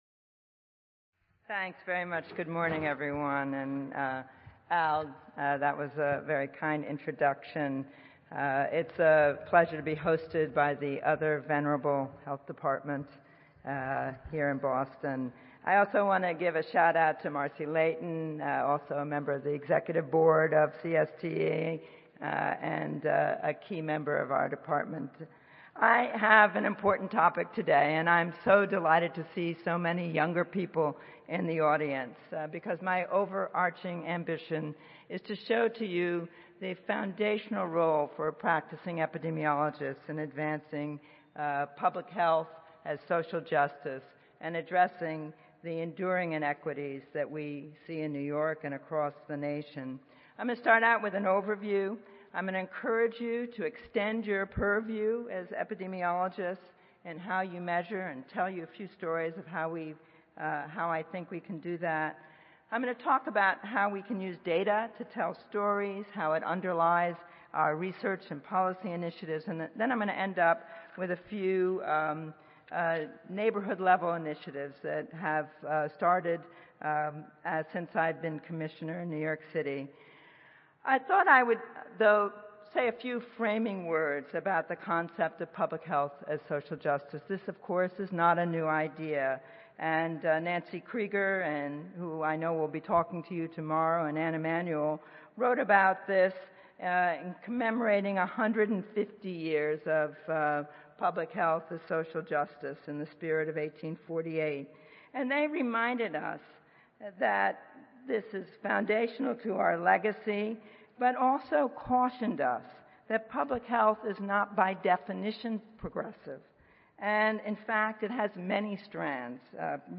Grand Ballroom, Sheraton Hotel
Recorded Presentation